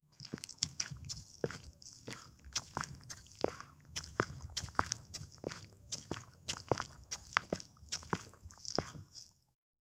Running footsteps – dirt crunch and breathing
running-footsteps--dirt-c-aufbr33a.wav